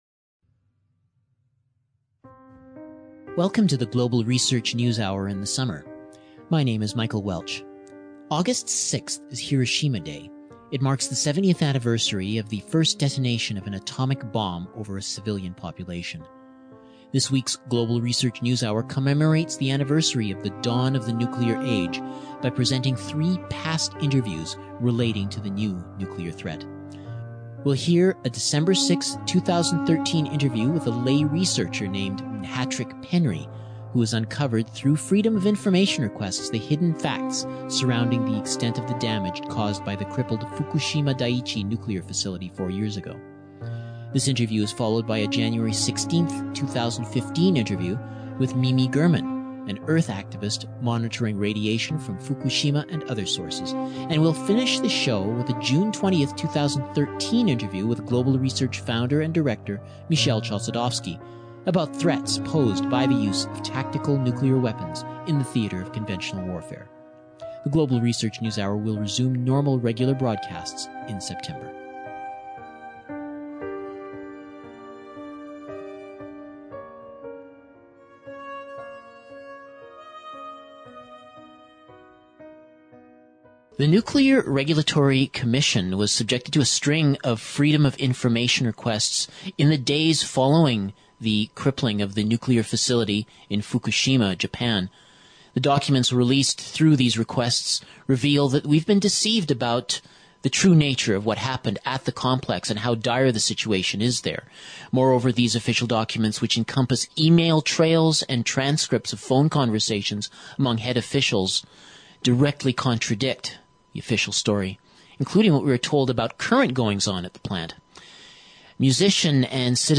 Three Interviews on Today's Nuclear Threat